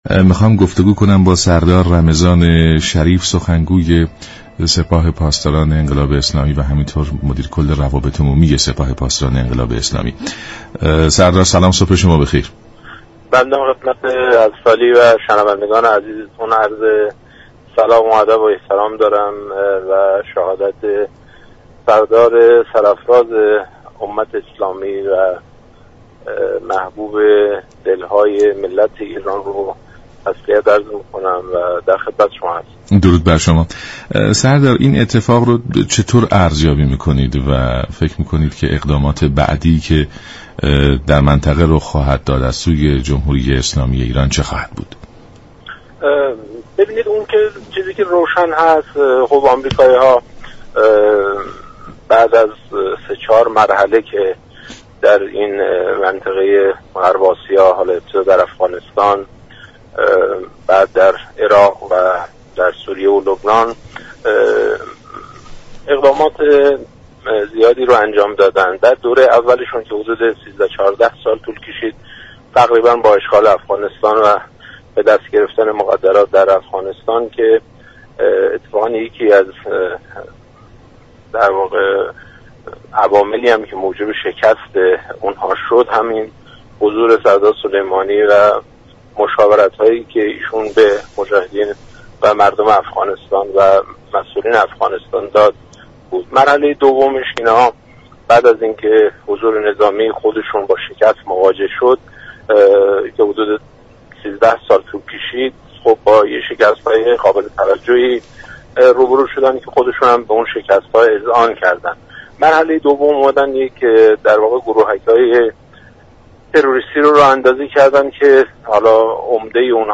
سخنگوی سپاه پاسداران انقلاب اسلامی در گفت و گو با رادیو ایران گفت: مردم با اراده های آهنین خود انتقام خون سردار سلیمانی خواهند گرفت.